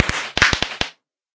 fireworks
twinkle1.ogg